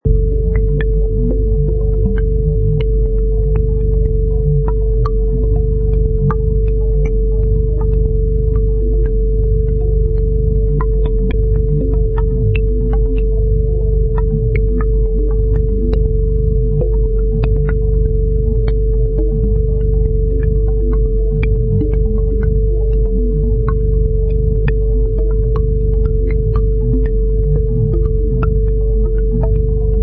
Deep and creepy evil bass sound LOW FREQUENCIES: USE HEADPHONES OR SUBWOOFER TO DEMO
Product Info: 48k 24bit Stereo
Category: Sound Design / Deep Tones
Try preview above (pink tone added for copyright).
Weird_Bassy_Shyat.mp3